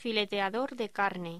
Locución: Fileteador de carne